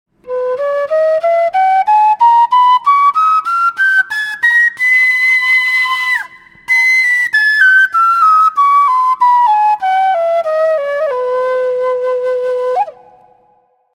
Кенилла (Quenilla, Chacon, C) Перу
Кенилла (Quenilla, Chacon, C) Перу Тональность: C
Материал: бамбук
Кенилла (Quenilla) - продольная флейта открытого типа, разновидность флейты кена.